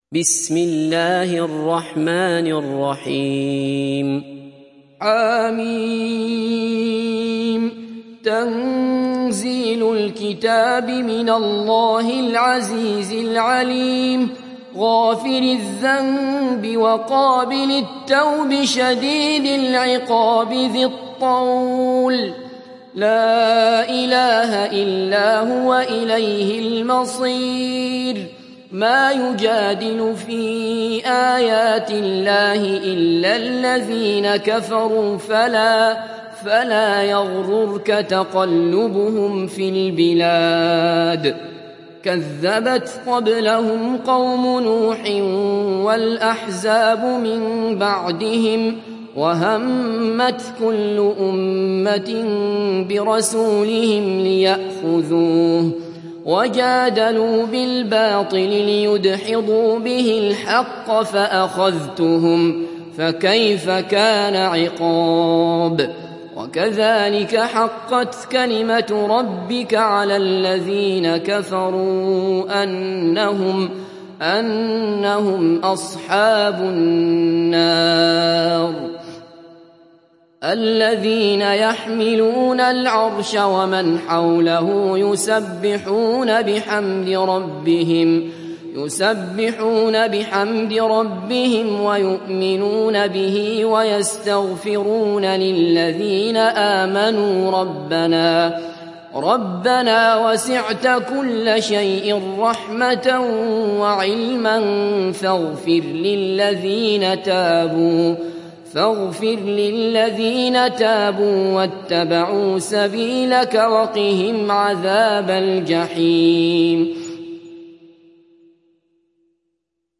تحميل سورة غافر mp3 بصوت عبد الله بصفر برواية حفص عن عاصم, تحميل استماع القرآن الكريم على الجوال mp3 كاملا بروابط مباشرة وسريعة